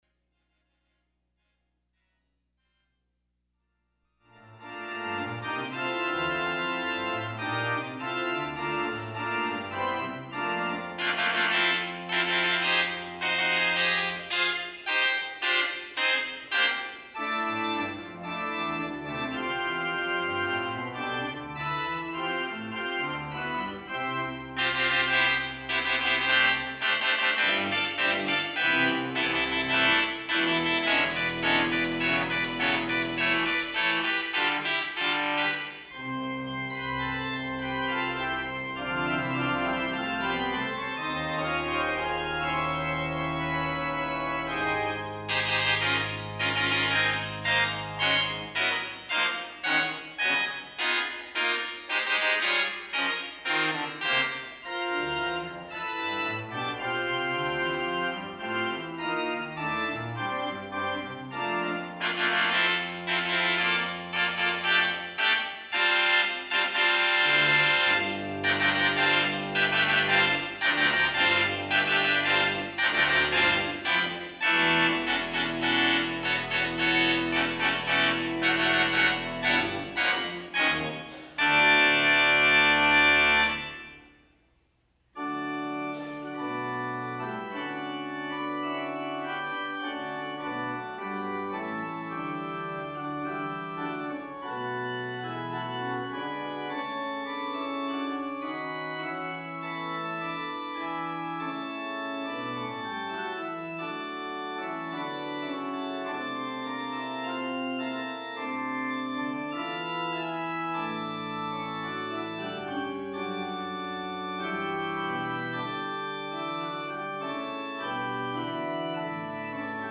July 7, 2019 Service
Traditional Sermon